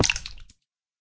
sounds / mob / guardian / land_hit3.ogg
land_hit3.ogg